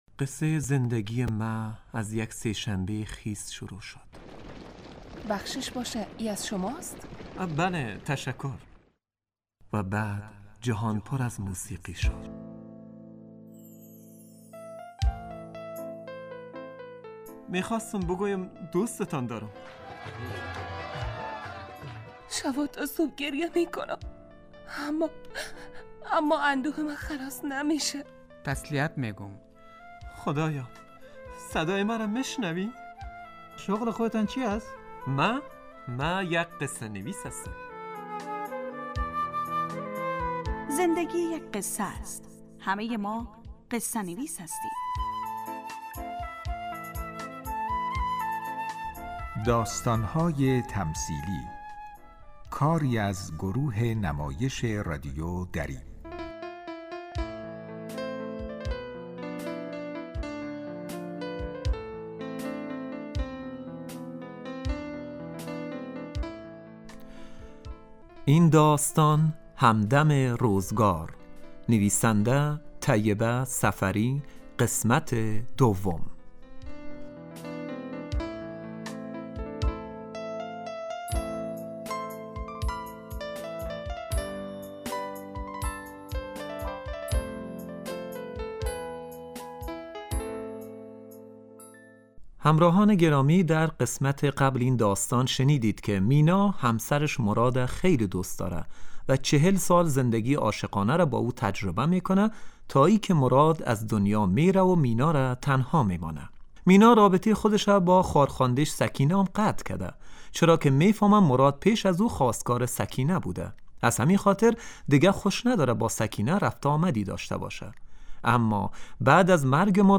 داستانهای تمثیلی نمایش 15 دقیقه ای هست که از شنبه تا پنج شنبه ساعت 03:20 عصربه وقت افغانستان پخش می شود.